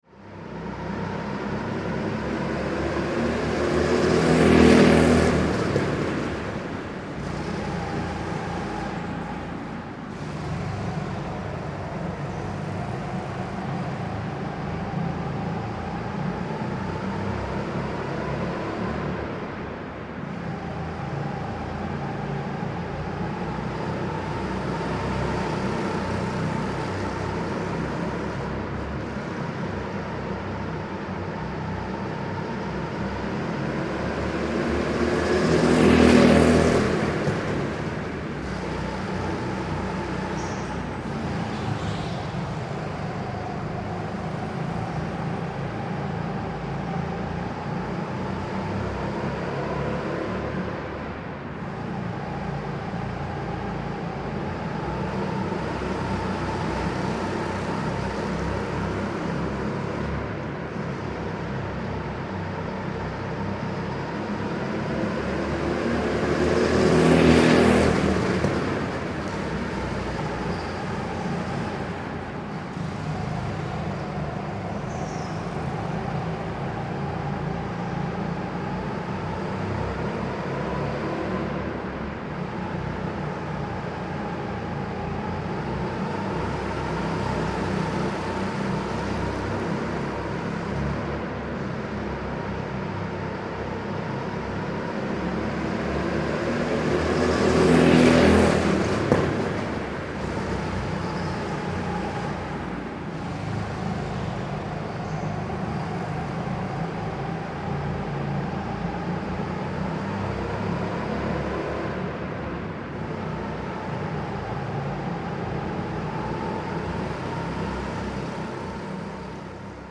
Атмосферный гул картинга